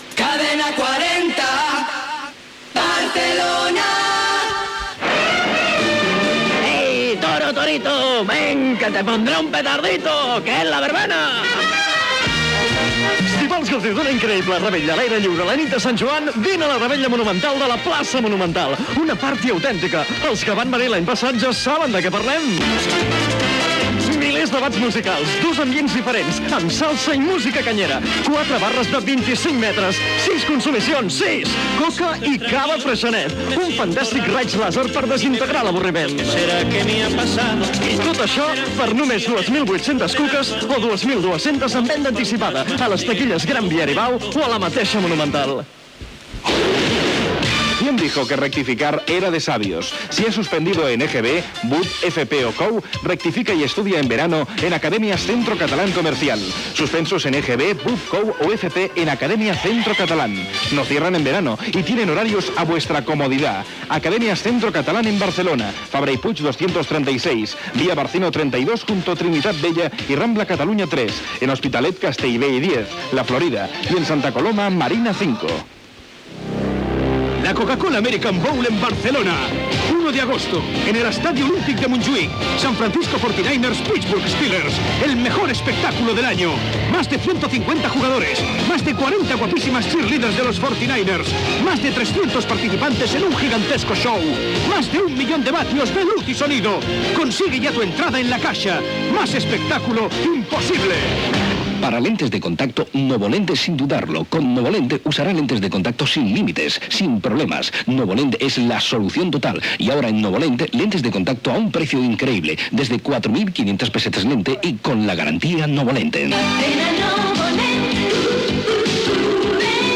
Indicatiu de l'emissora, publicitat
FM